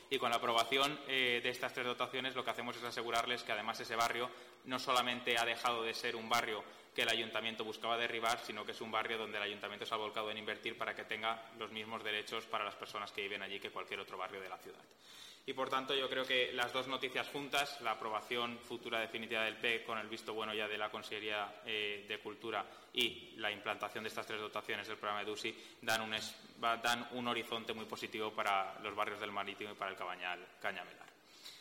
Así lo ha anunciado el concejal de Desarrollo Innovador de los Sectores Económicos, Borja Sanjuan, en la rueda de prensa ofrecida junto al vicealcalde y concejal de Ecología Urbana, Sergi Campillo, tras la reunión de la Junta.